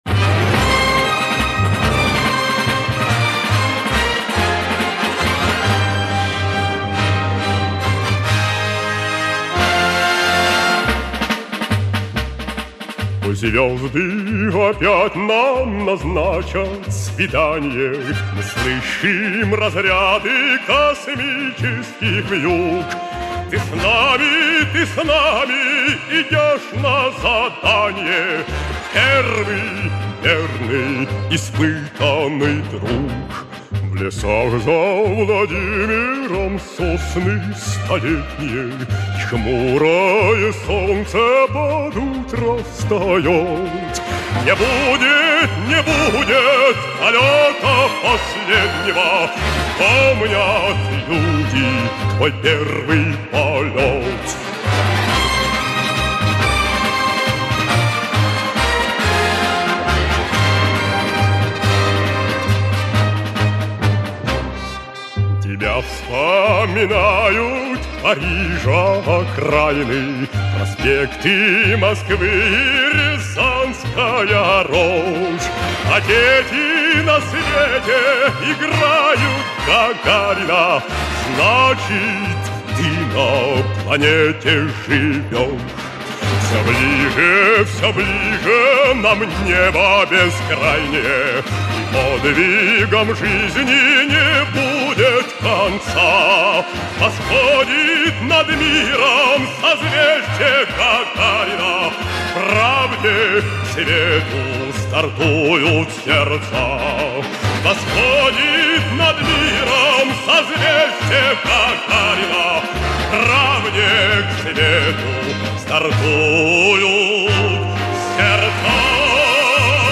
Концертный вариант 1973 года: